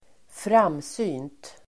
Ladda ner uttalet
Folkets service: framsynt framsynt adjektiv, far-sighted Uttal: [²fr'am:sy:nt] Böjningar: framsynt, framsynta Definition: förutseende Avledningar: framsynthet (far-sightedness) far-sighted adjektiv, framsynt
framsynt.mp3